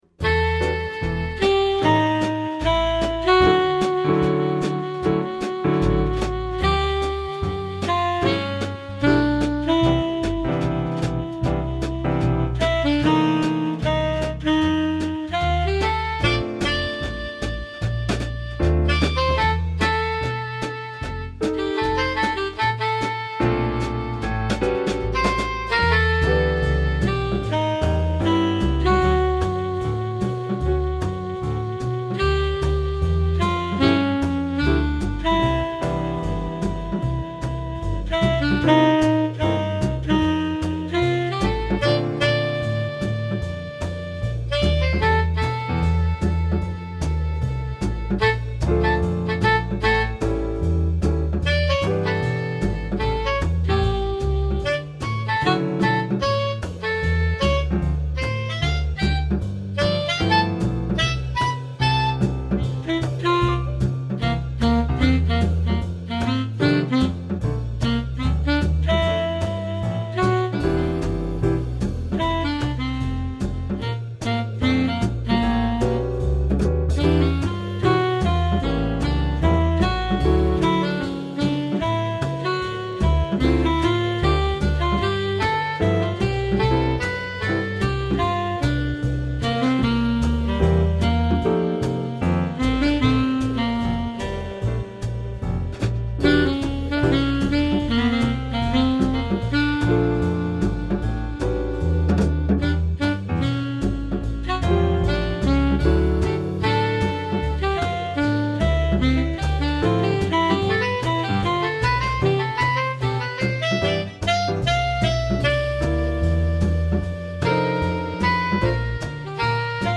fast bop
at about 155BPM, breaking down into a jam after 16 bars